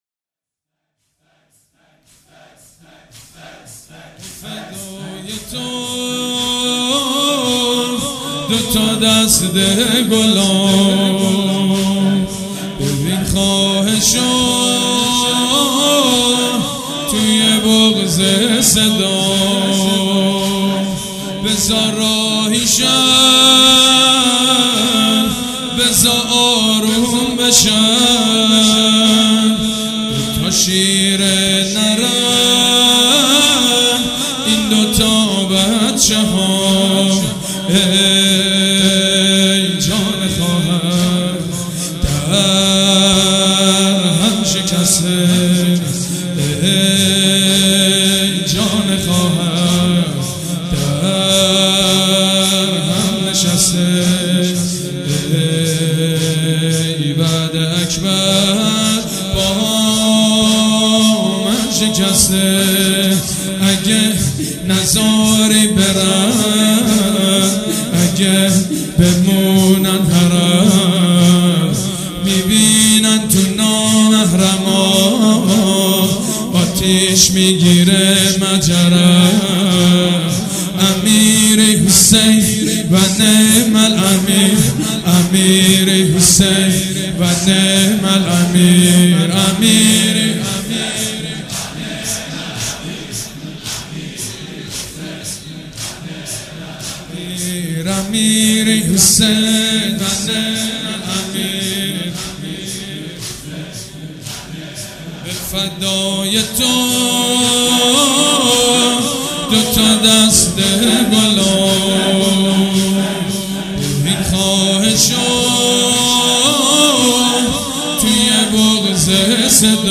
صوت مراسم شب چهارم محرم 1438هیئت ریحانة الحسین(ع) ذیلاً می‌آید: